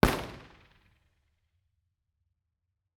IR_EigenmikeHHR2_processed_Bformat.wav